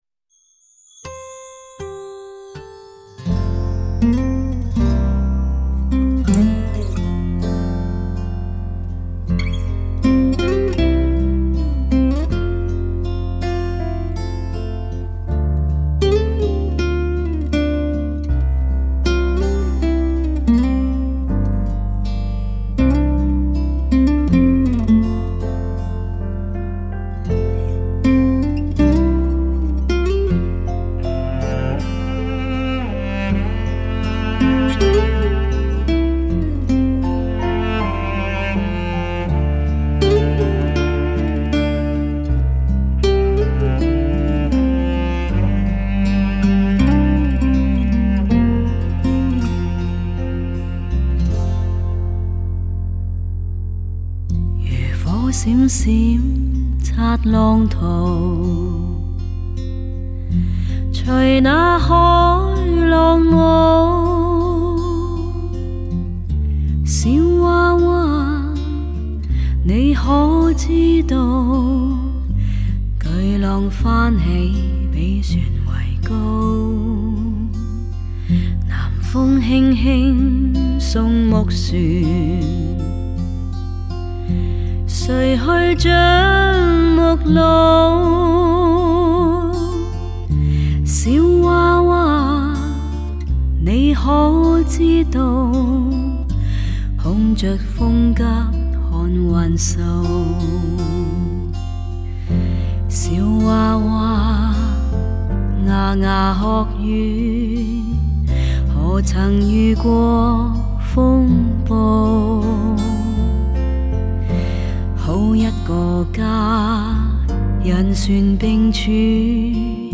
典雅醇厚、感人至深、独具一格的演唱功力，定位精准，音色通透自然，音乐更显婉转隽永。